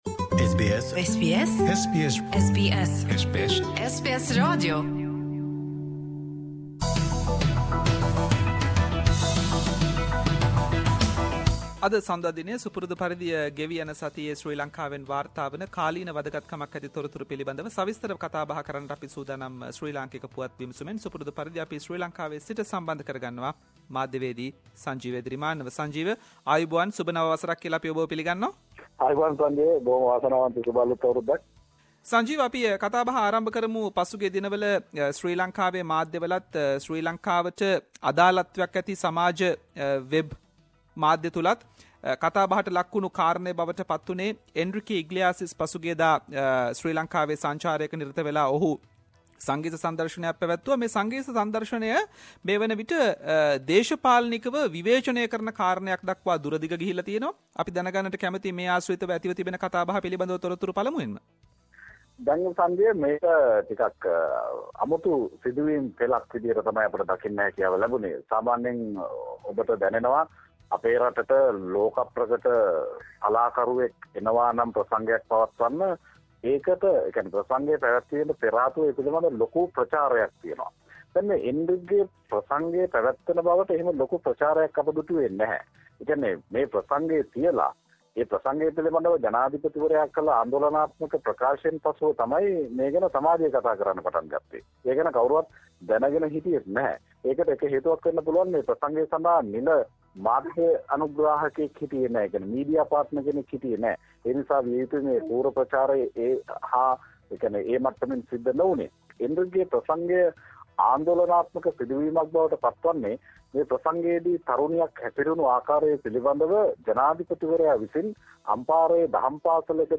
SBS Sinhalese Weekly Sri Lankan News Wrap